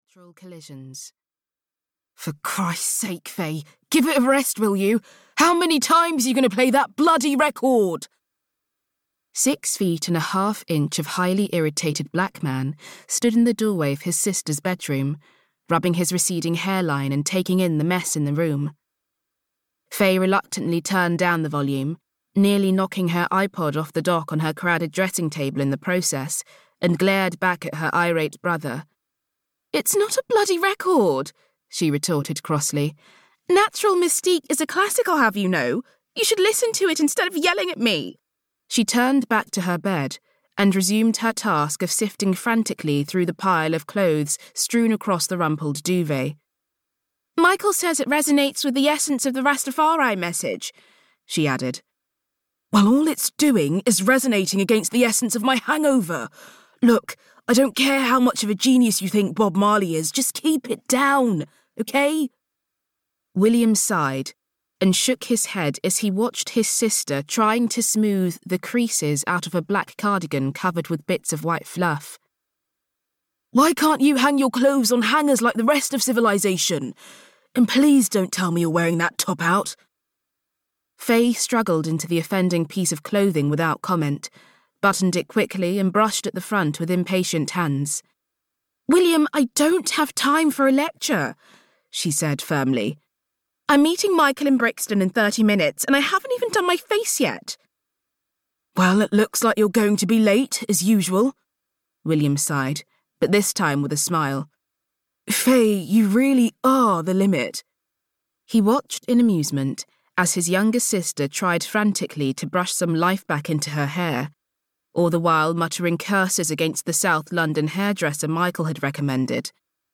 Audio knihaFrom Pasta to Pigfoot (EN)
Ukázka z knihy